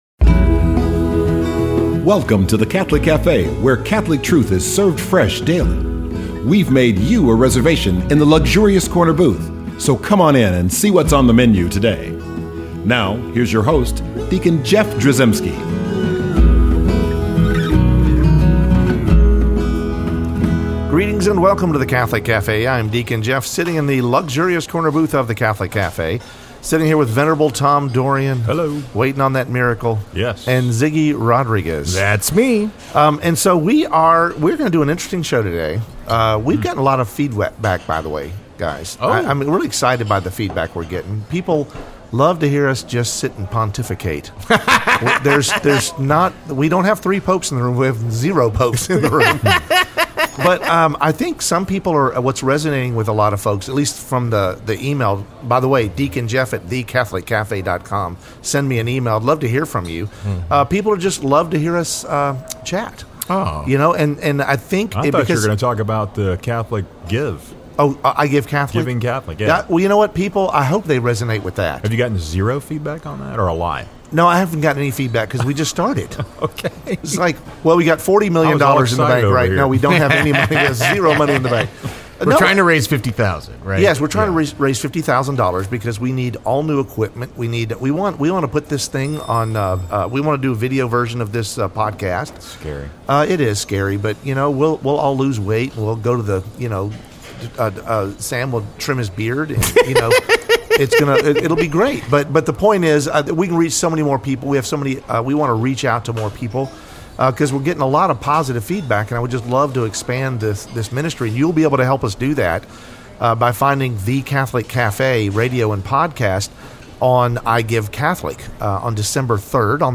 The Catholic Cafe radio show is a 30 minute program designed to convey the Truth of Catholic Doctrine and Teaching in an informal, conversational format.